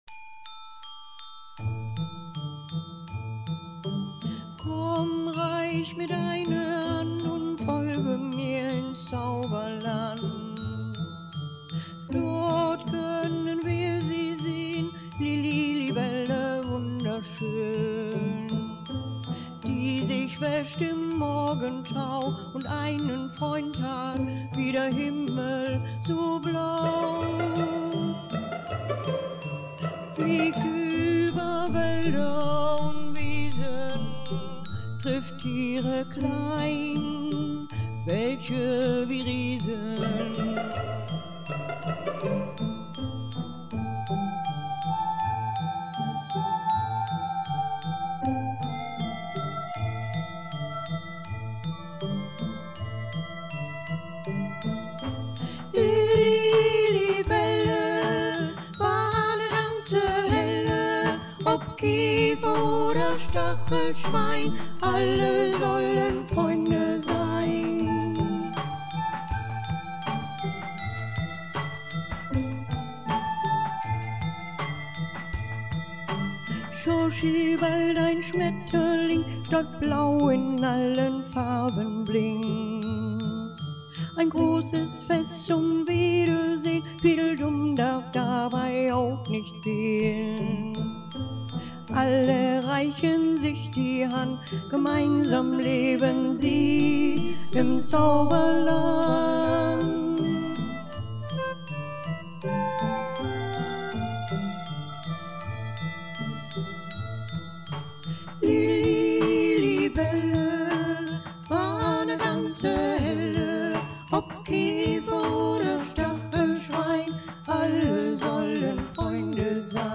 Lilli Libelle Hörbuch